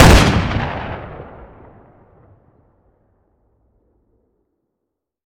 weap_western_fire_plr_atmo_ext1_06.ogg